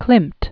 (klĭmt), Gustav 1862-1918.